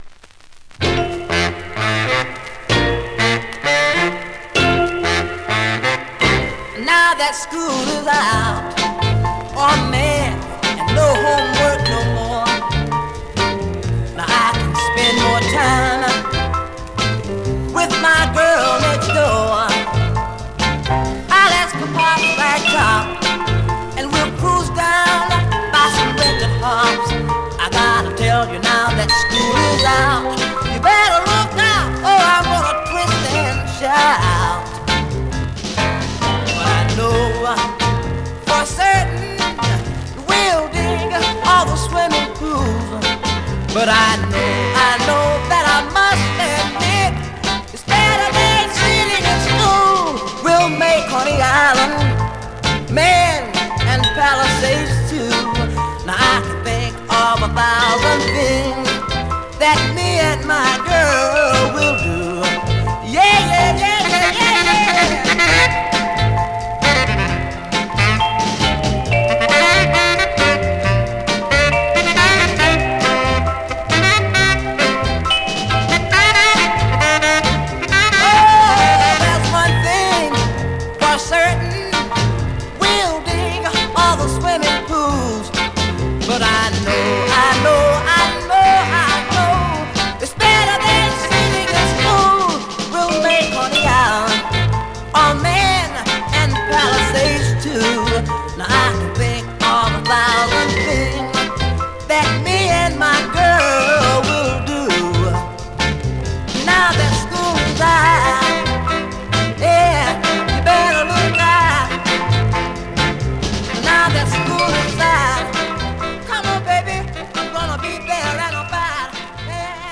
Vinyl has a few light marks plays great .
Great mid-tempo Rnb / Doo-Wop dancer .
R&B, MOD, POPCORN